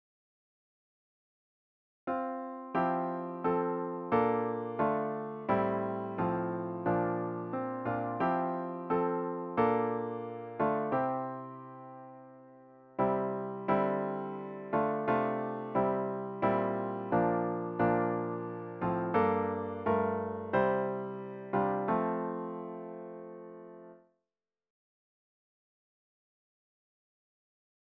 The hymn should be performed at a reflective ♩= ca. 88.